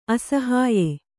♪ asahāye